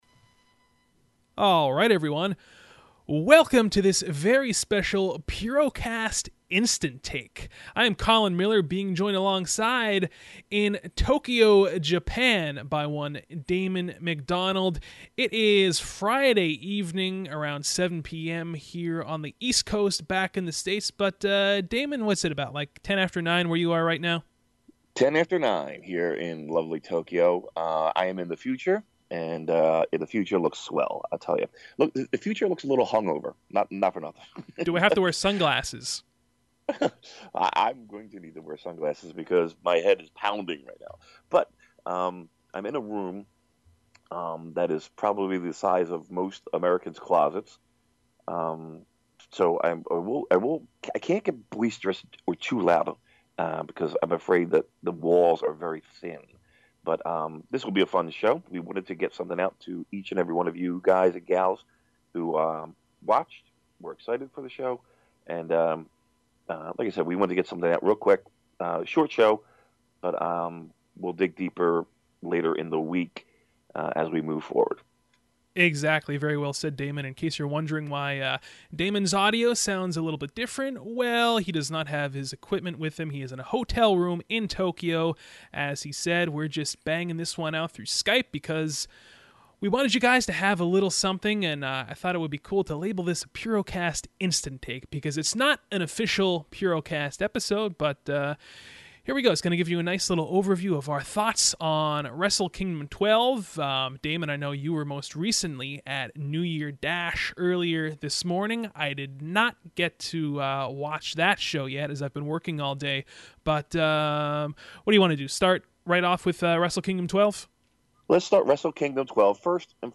on the line from Tokyo